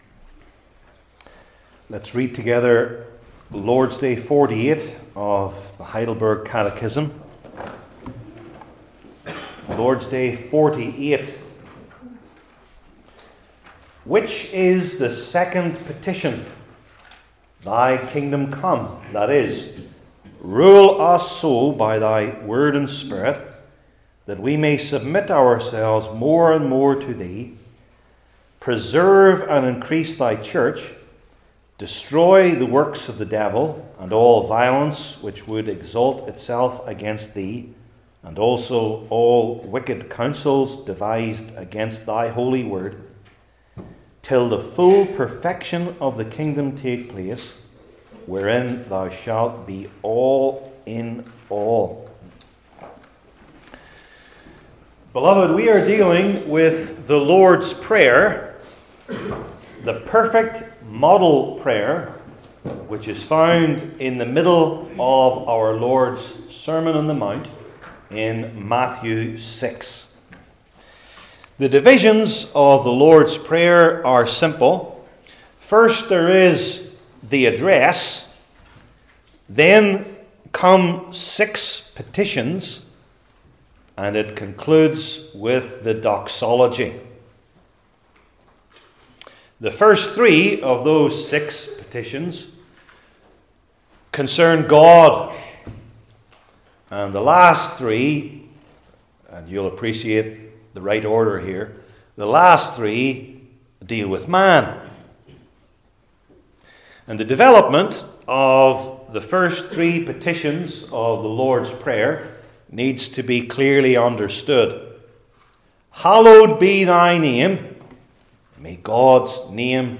Heidelberg Catechism Sermons I. The Meaning of the Kingdom II.